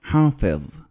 Islamic vocabulary pronunciation